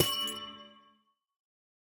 Minecraft Version Minecraft Version snapshot Latest Release | Latest Snapshot snapshot / assets / minecraft / sounds / block / amethyst_cluster / break3.ogg Compare With Compare With Latest Release | Latest Snapshot
break3.ogg